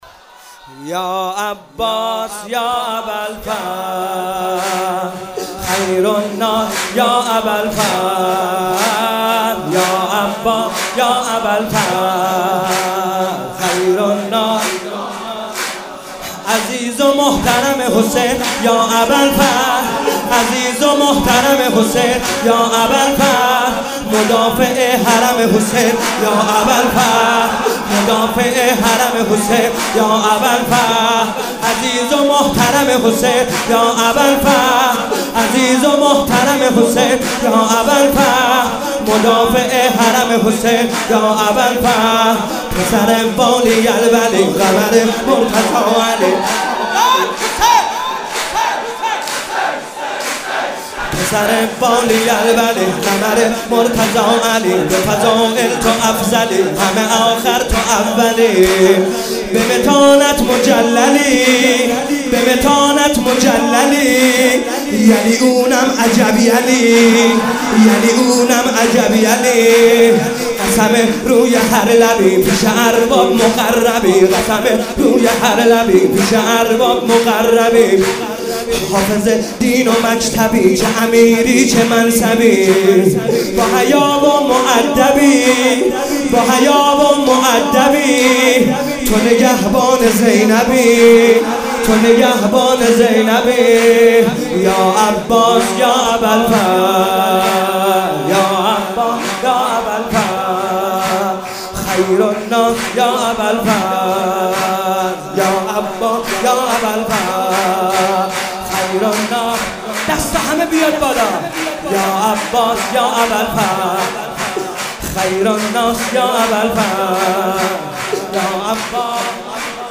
قالب : سرود